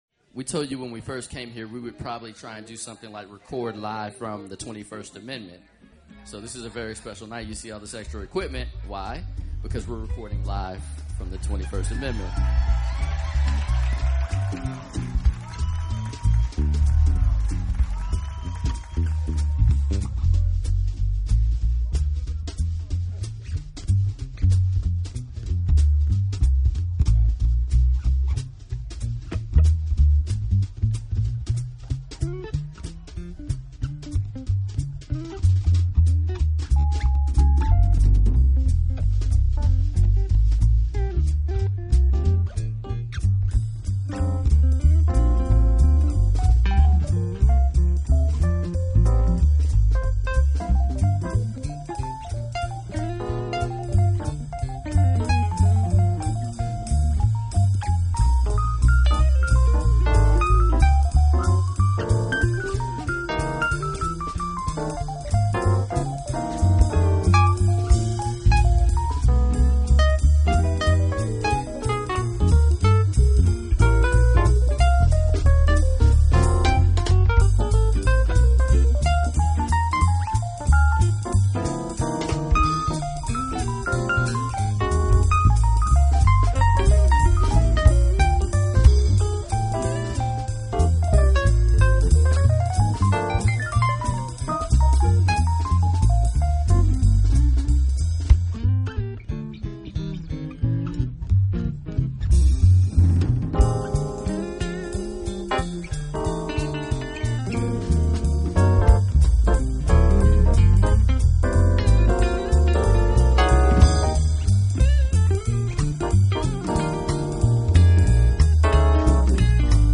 专辑风格：Smooth Jazz